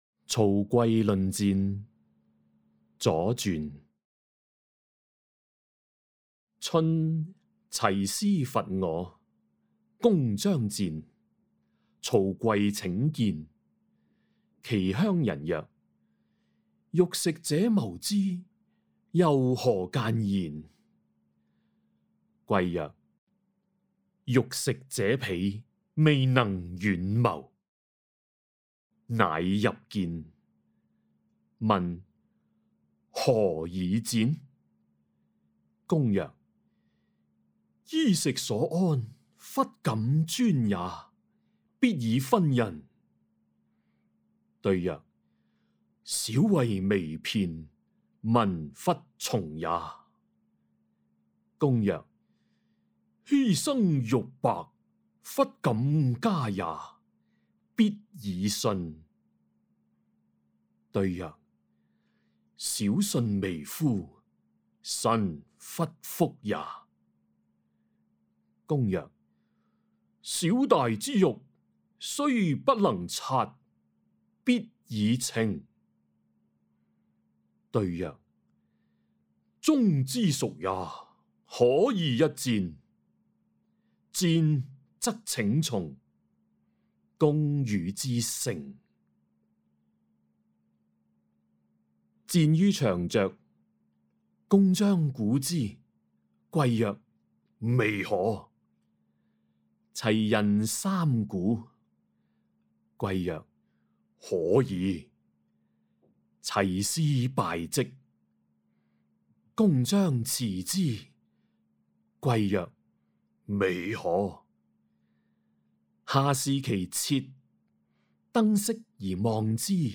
誦讀錄音
(粵語台詞誦)